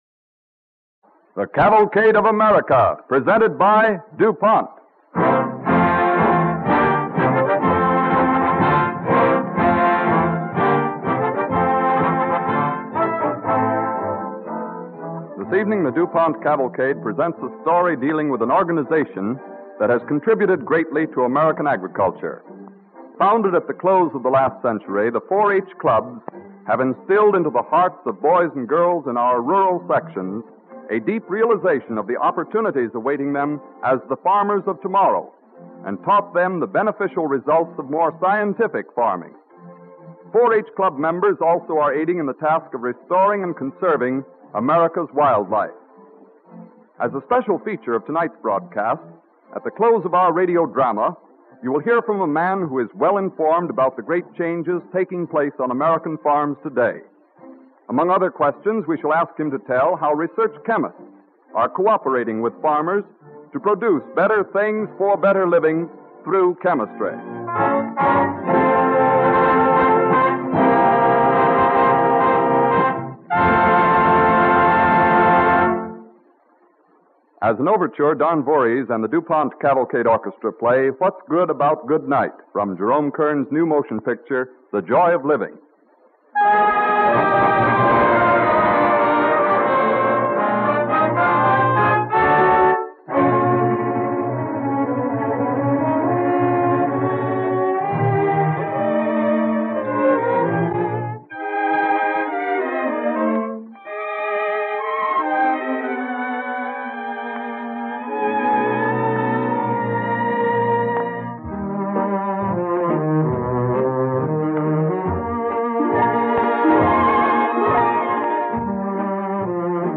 Listen to and download the Cavalcade of America Radio Program, The 4-H Club Movement